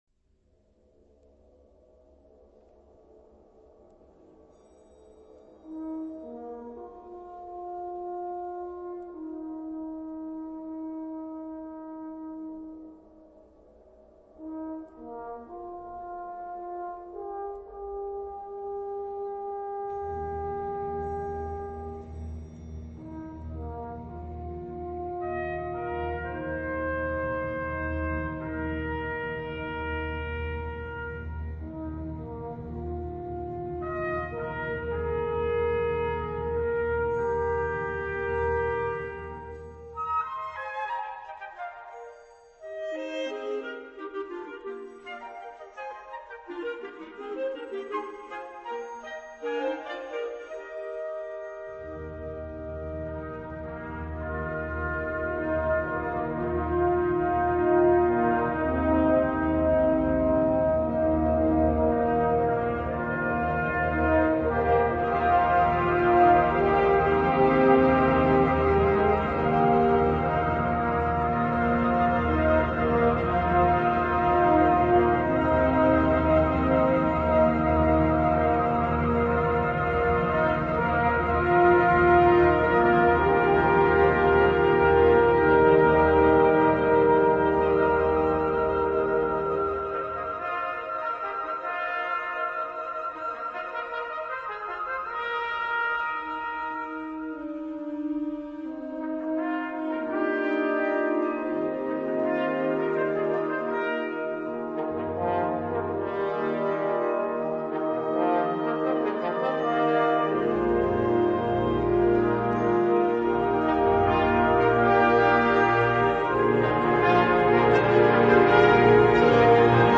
Gattung: Konzertstück
Besetzung: Blasorchester